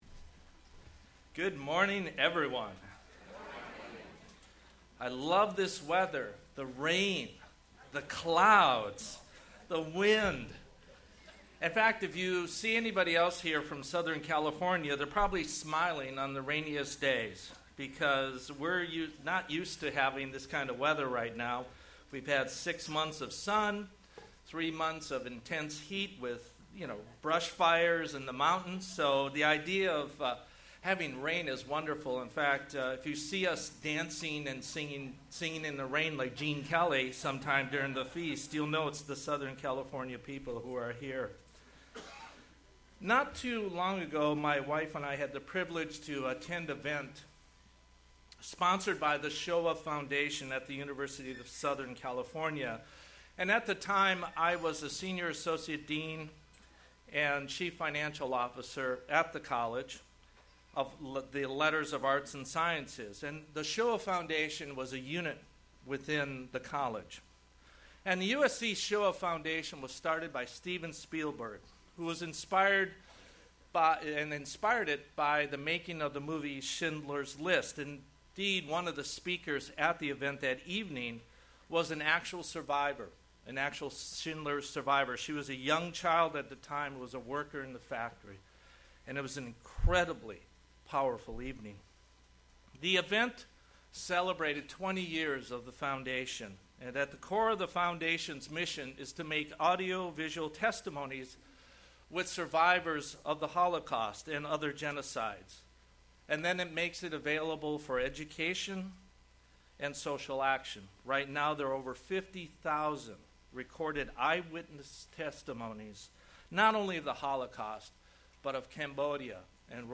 This sermon was given at the Victoria, British Columbia 2016 Feast site.